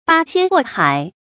注音：ㄅㄚ ㄒㄧㄢ ㄍㄨㄛˋ ㄏㄞˇ
八仙過海的讀法